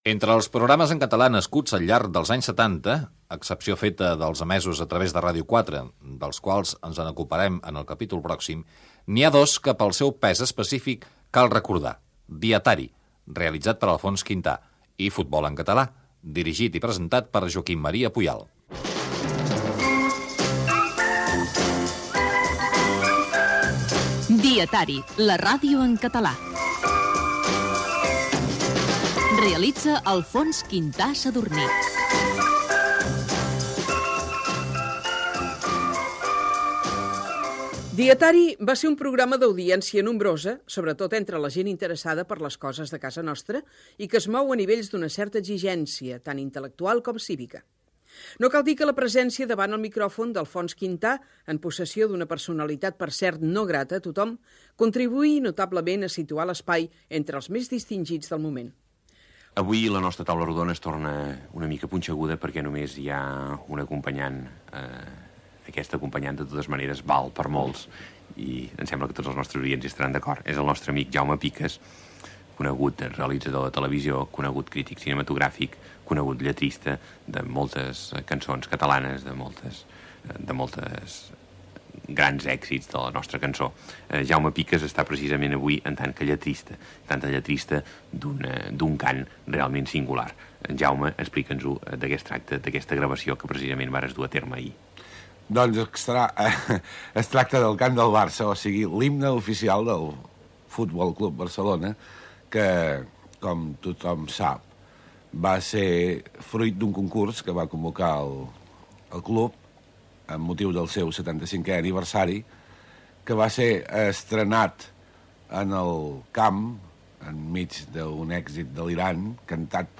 Gènere radiofònic
Divulgació
FM